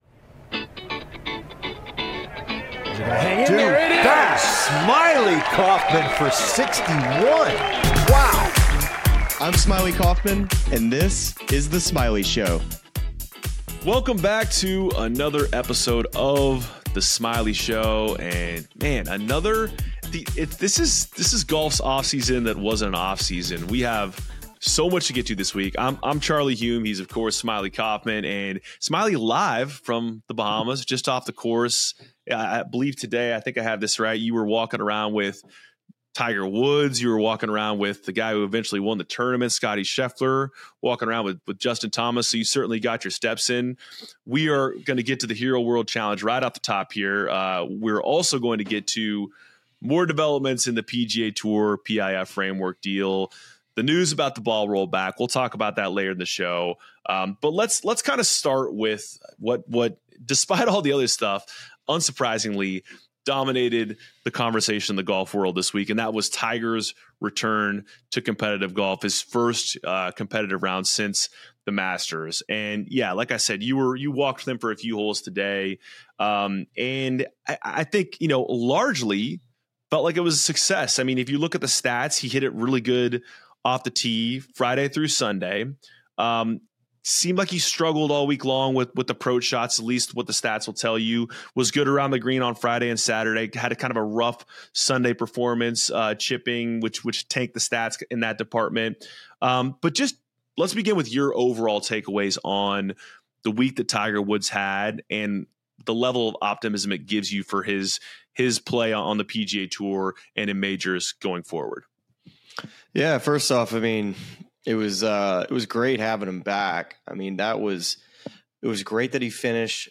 " with Smylie's live from the Hero World Challenge in the Bahamas.